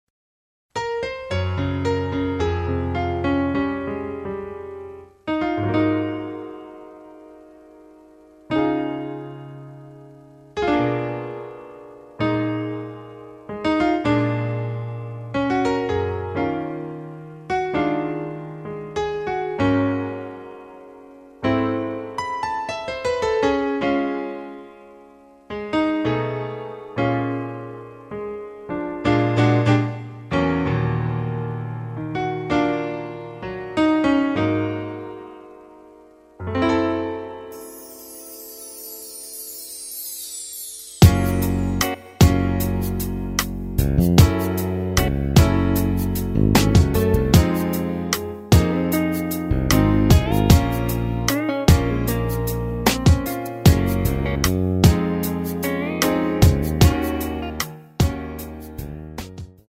Db
앞부분30초, 뒷부분30초씩 편집해서 올려 드리고 있습니다.
중간에 음이 끈어지고 다시 나오는 이유는